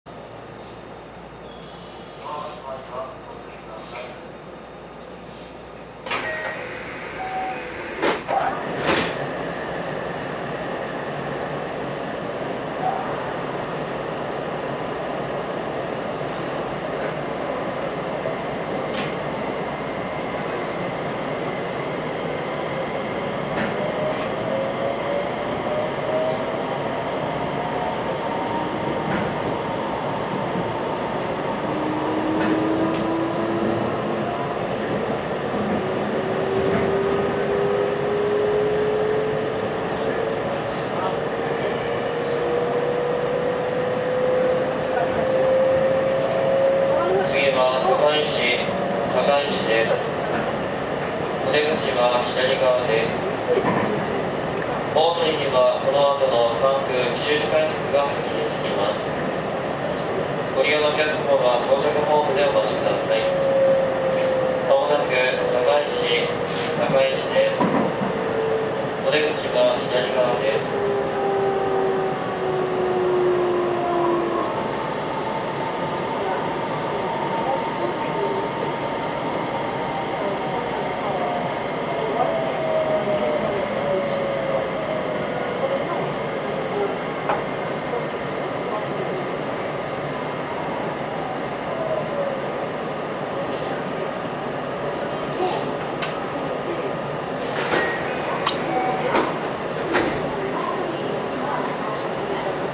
走行音
録音区間：浅香～堺市(お持ち帰り)